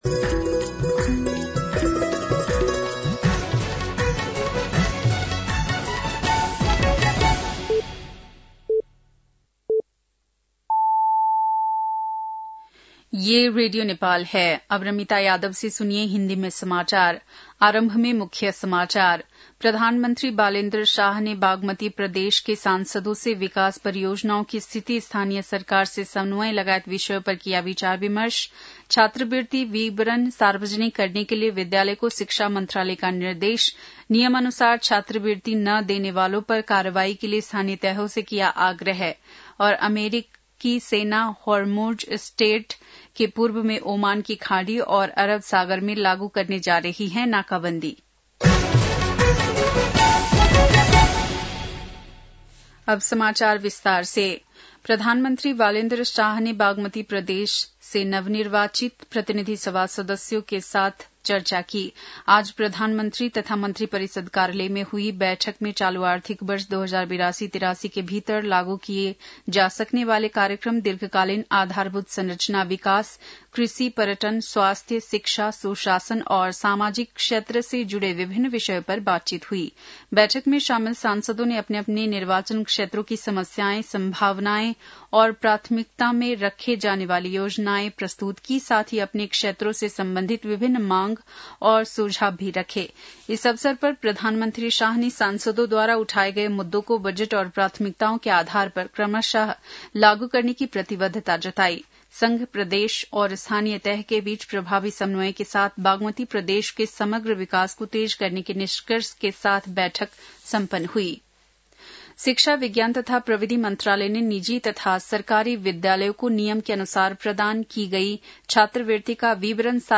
बेलुकी १० बजेको हिन्दी समाचार : ३० चैत , २०८२